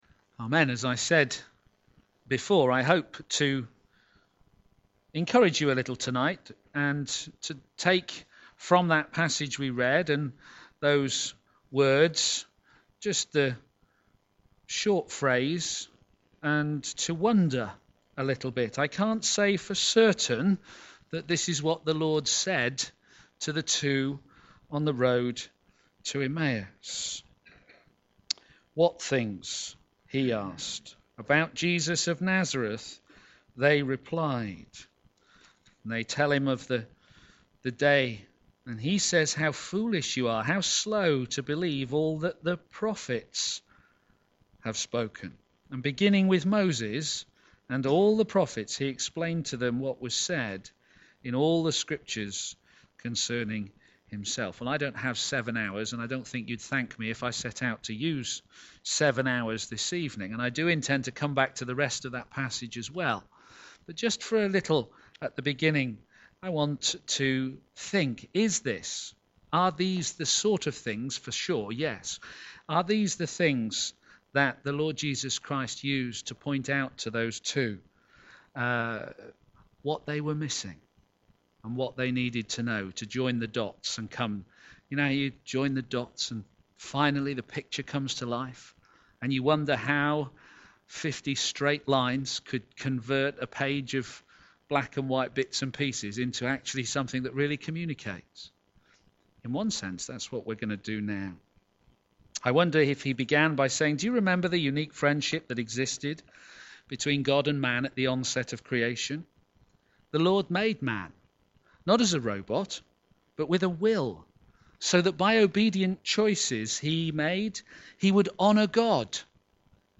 He is Risen! (2) Sermon